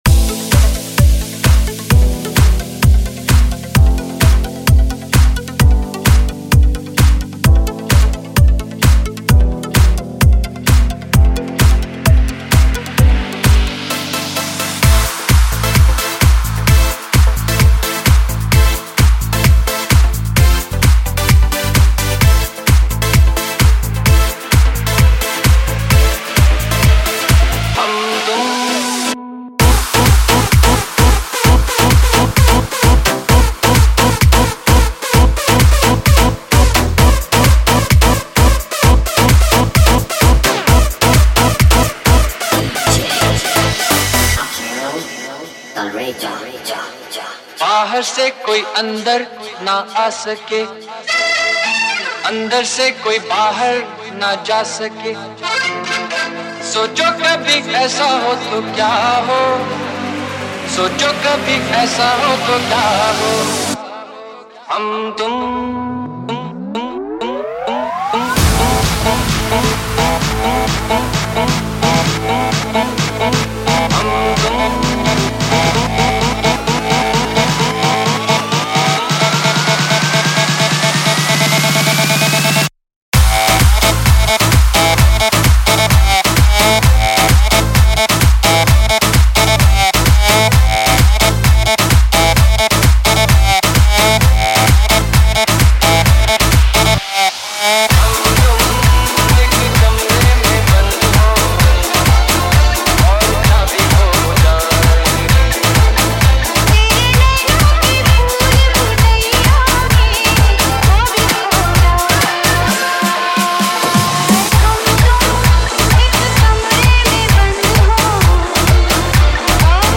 Bollywood DJ Remix